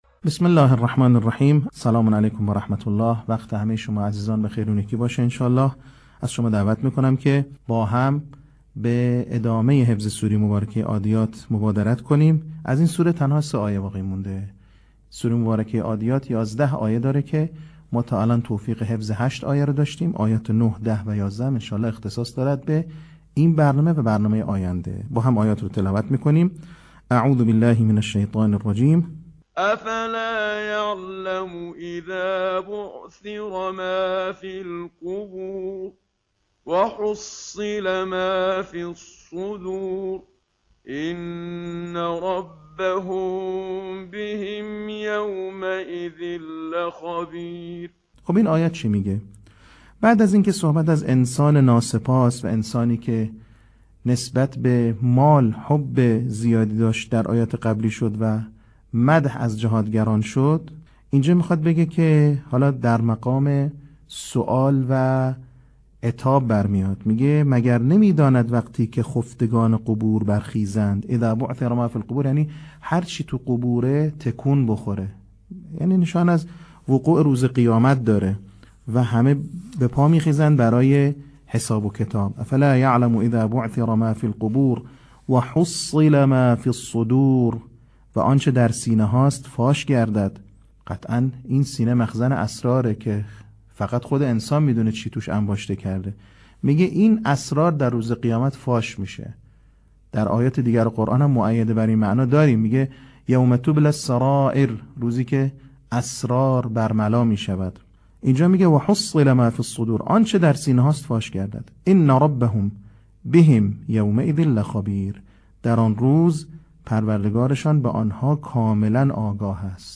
صوت | بخش پنجم آموزش حفظ سوره عادیات
به همین منظور مجموعه آموزشی شنیداری (صوتی) قرآنی را گردآوری و برای علاقه‌مندان بازنشر می‌کند.